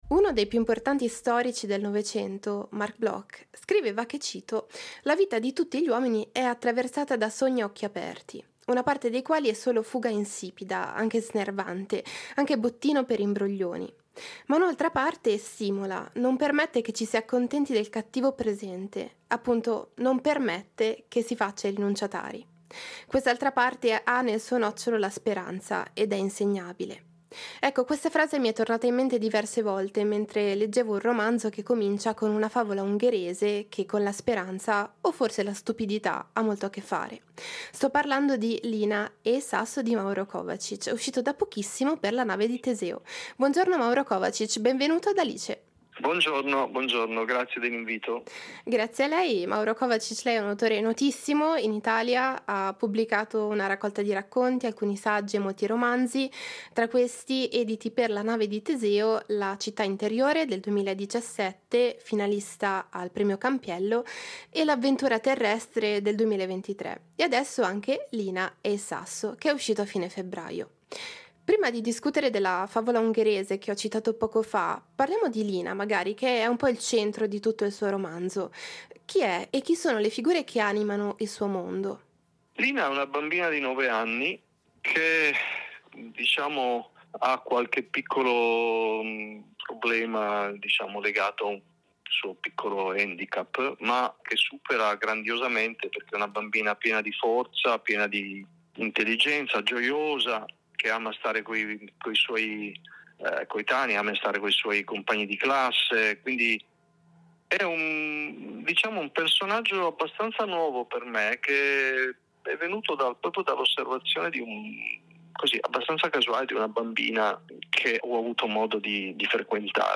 Intervista a Mauro Covacich